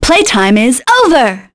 Maya-Vox_Skill3.wav